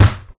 thud_5.ogg